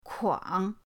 kuang3.mp3